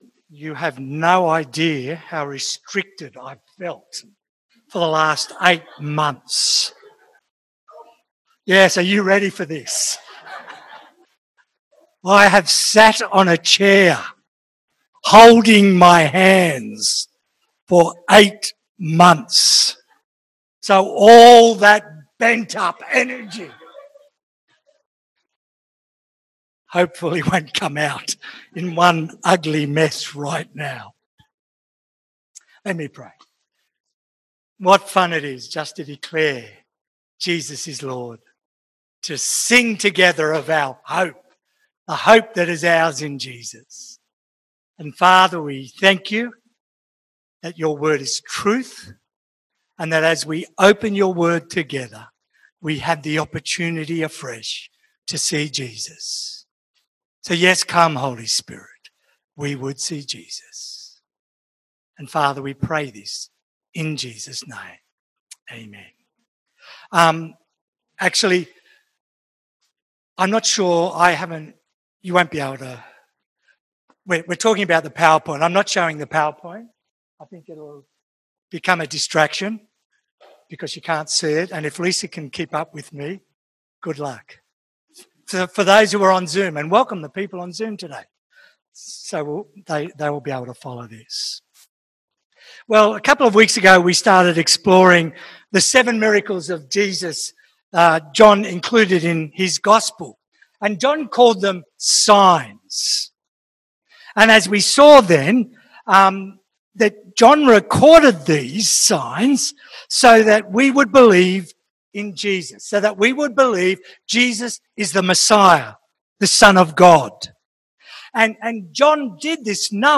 Categories Sermon Leave a Reply Cancel reply Your email address will not be published.